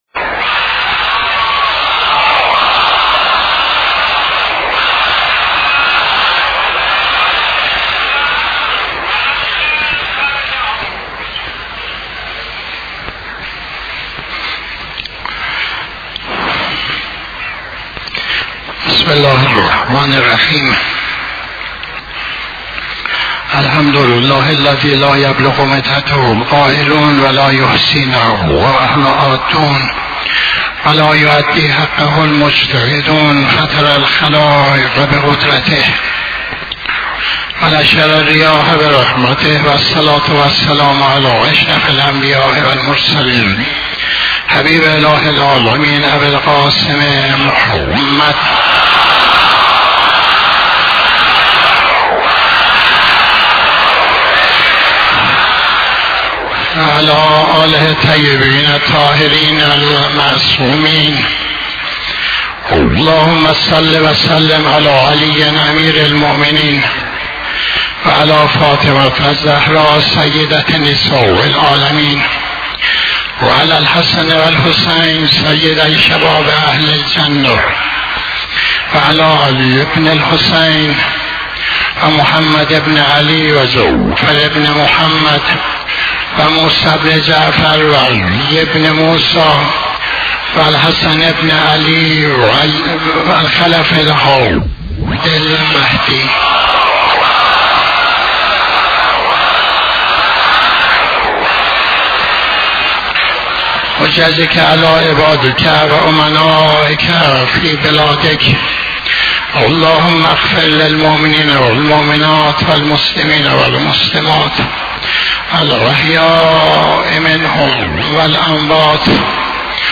خطبه دوم نماز جمعه 14-12-84